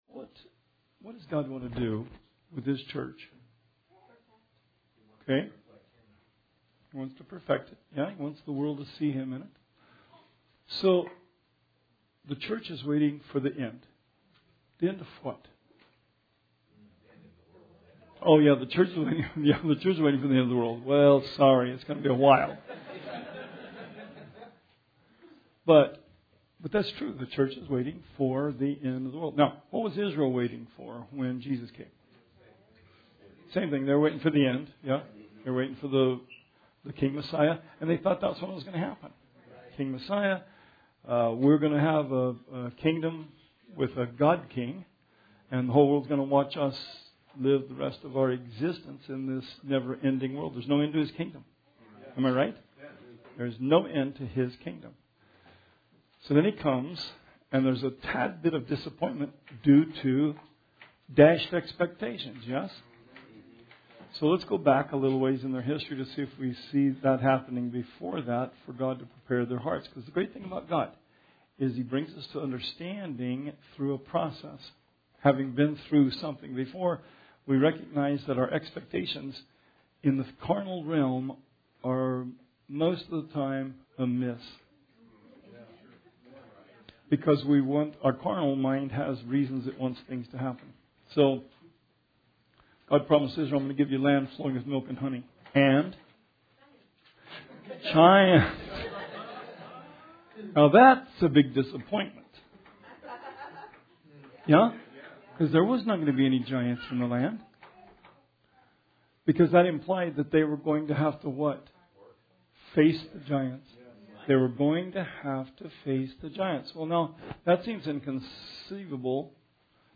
Bible Study 4/10/19